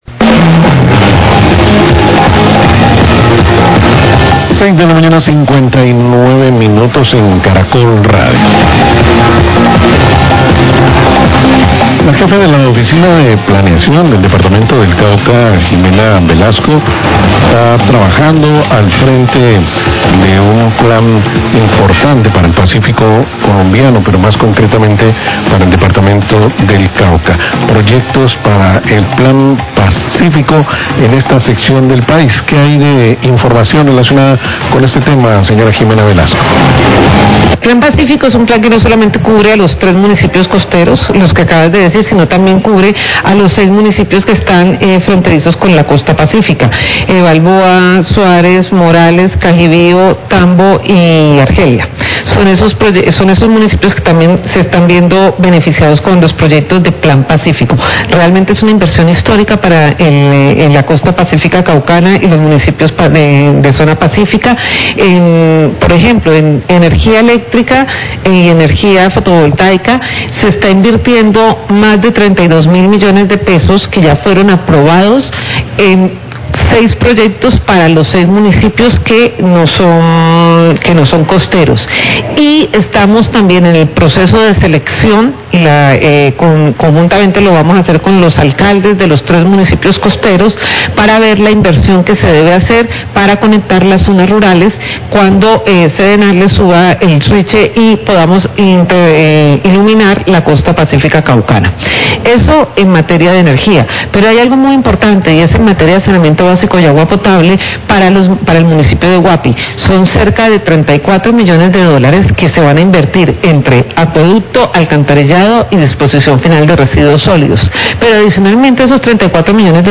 Radio
La Jefe de Planeación del Cauca, Jimena Velasco, habla de la evaluación de las acciones desarrolladas en el marco de la ejecución del Plan Todos Somos Pazcífico, que tiene como objetivo la financiación e inversión en varios temas como en seis proyectos de electrificación e intercnexión en la zona pacífica del departmento.